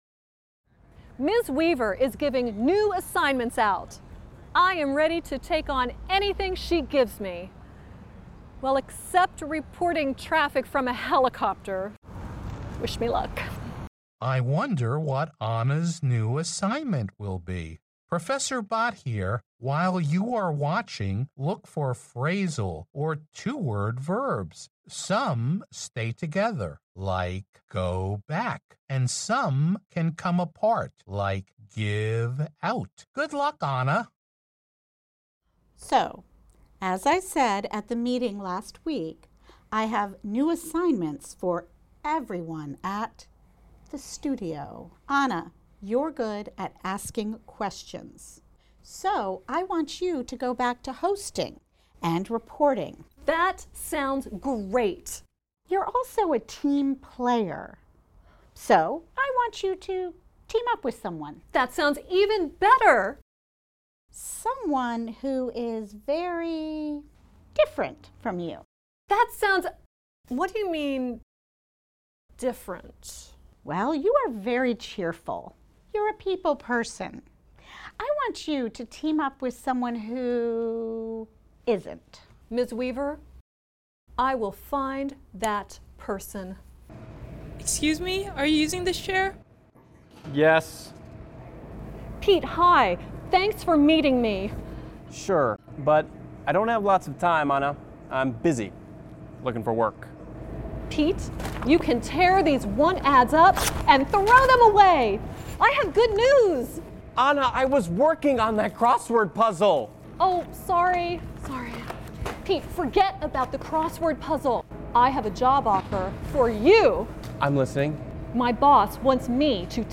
Hội thoại (Conversation)